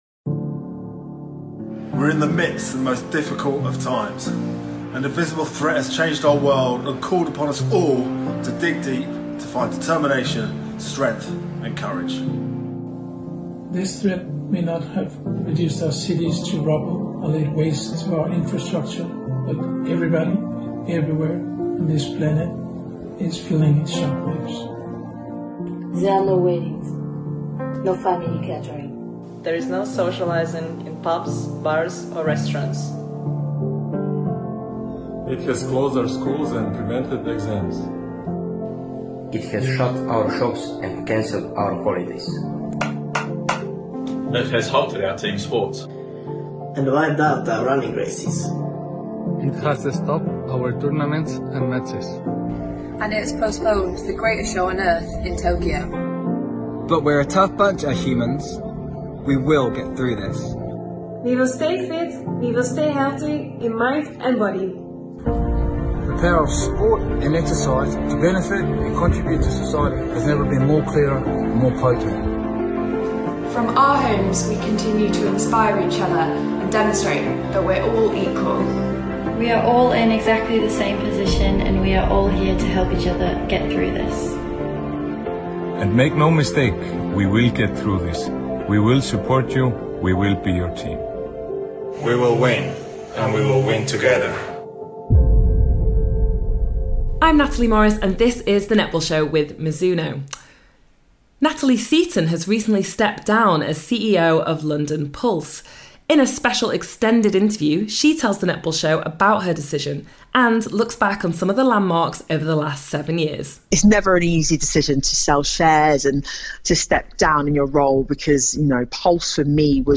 An extended interview